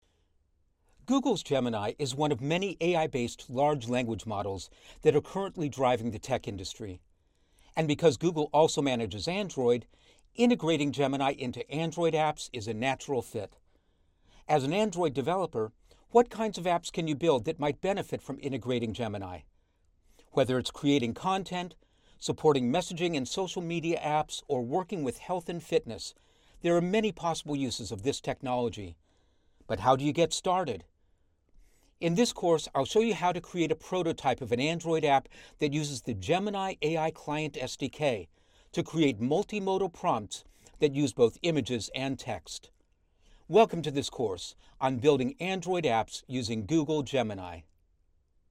Video Call
English - USA and Canada
Middle Aged